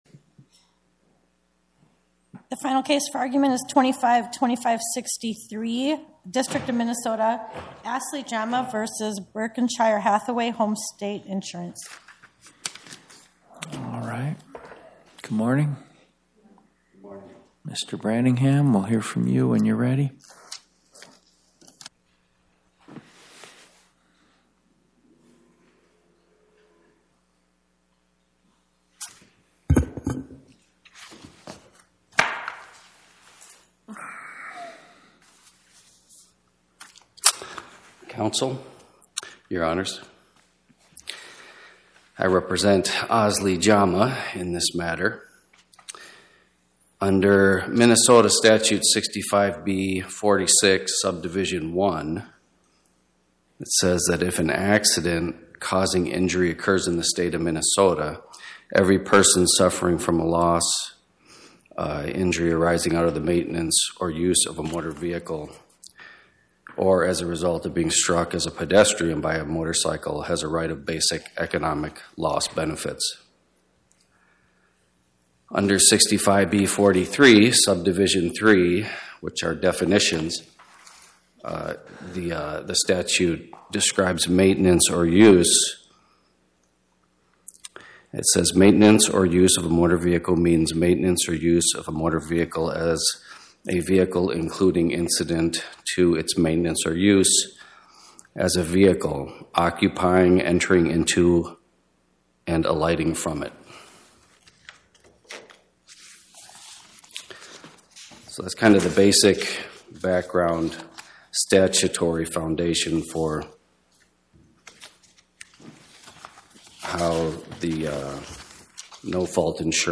Co. Podcast: Oral Arguments from the Eighth Circuit U.S. Court of Appeals Published On: Thu Mar 19 2026 Description: Oral argument argued before the Eighth Circuit U.S. Court of Appeals on or about 03/19/2026